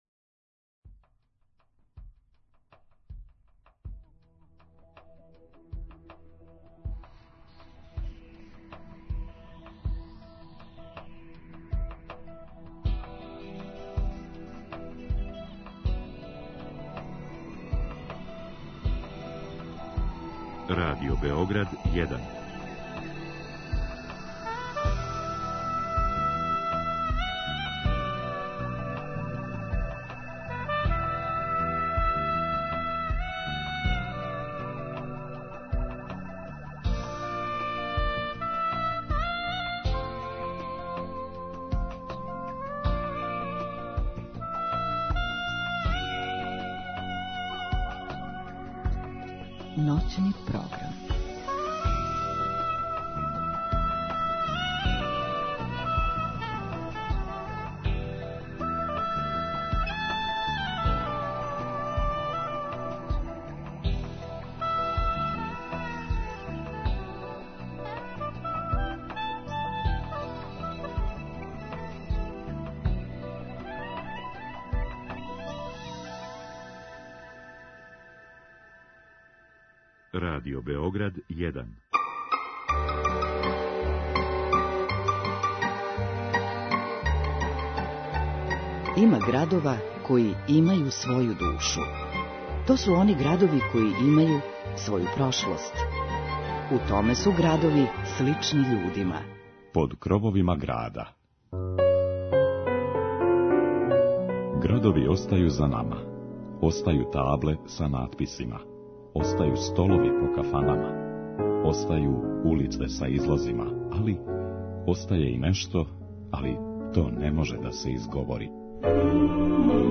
изворне народне музике